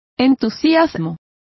Complete with pronunciation of the translation of excitements.